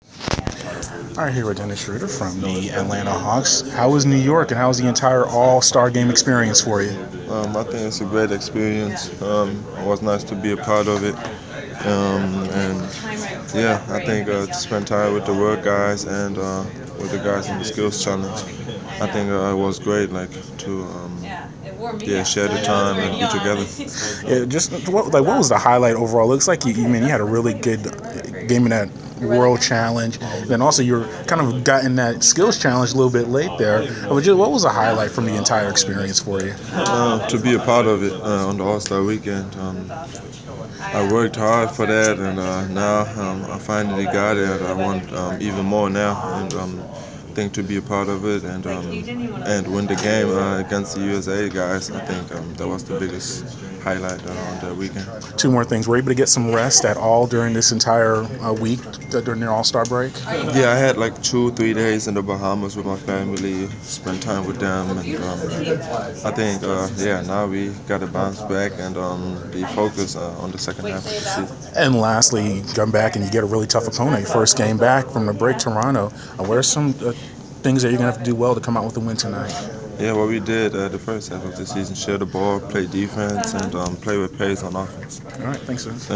Inside the Inquirer: Pregame interview with Atlanta Hawks’ Dennis Schroder (2/20/15)
hawks-pregame-toronto-schroeder.wav